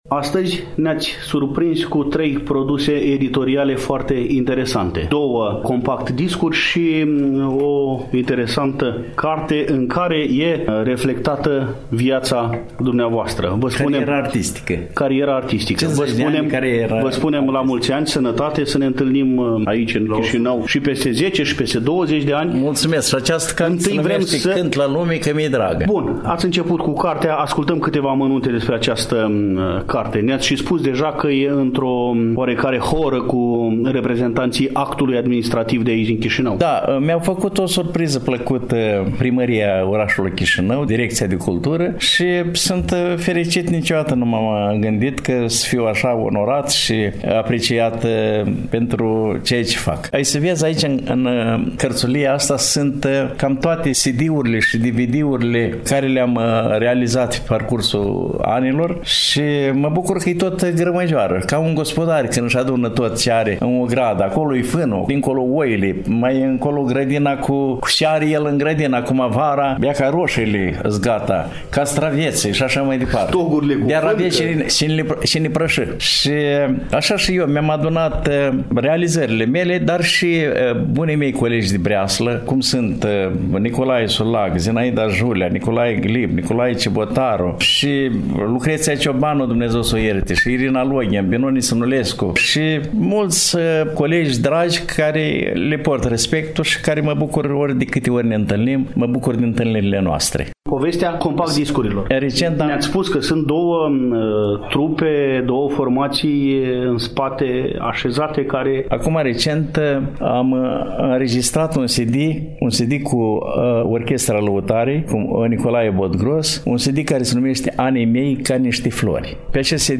2_Dialog-Interpret-de-Muzica-Populara-Mihai-Ciobanu-4-38.mp3